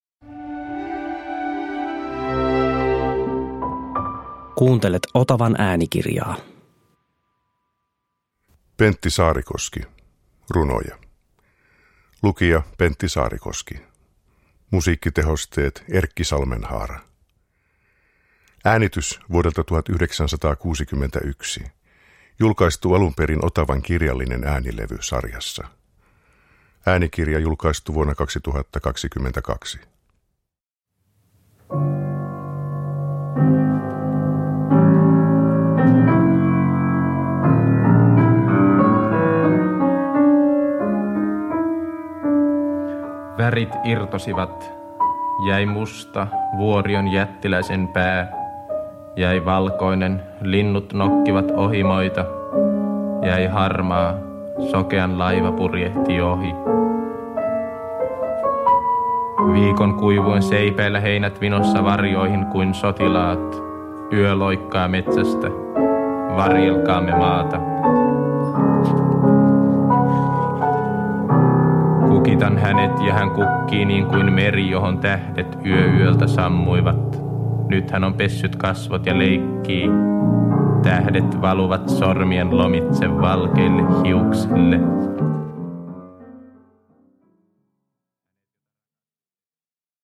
Runoja – Ljudbok – Laddas ner
Saarikoski lukee Saarikoskea.
Tälle äänitteelle vuodelta 1961 Pentti Saarikoski on itse lukenut 21 runoaan, jotka ovat kokoelmista Runoja, Toisia runoja, Runot ja Hipponaksin runot ja Maailmasta.
Pianomusiikkitehosteet on tehnyt säveltäjä Erkki Salmenhaara (1941-2002).
Uppläsare: Pentti Saarikoski, A. W. Yrjänä